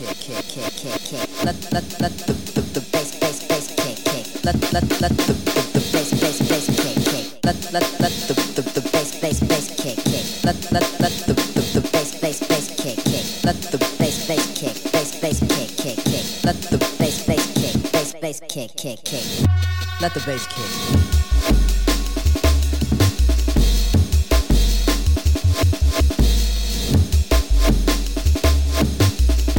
TOP > Jungle